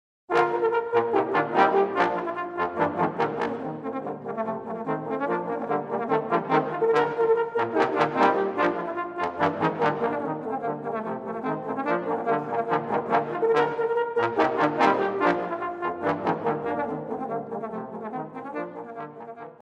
For Trombone Quartet